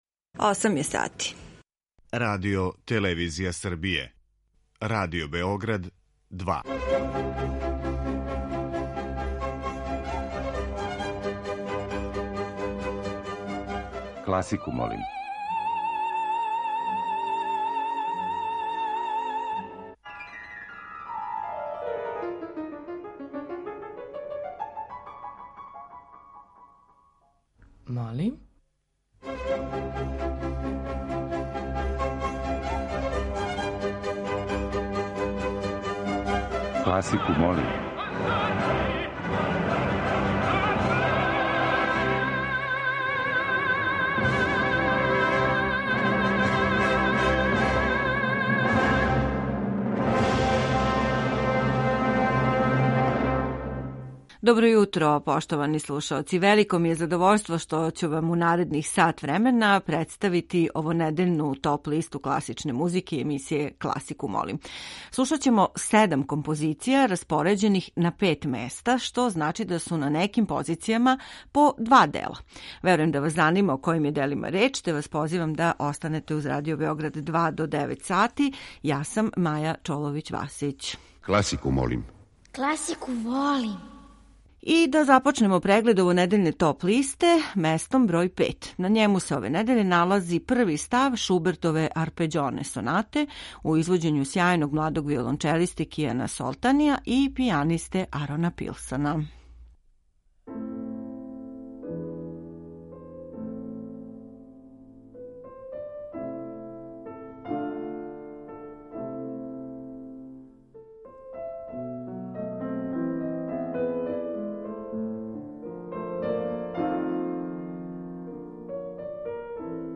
Данас емитујемо недељну топ листу класичне музике.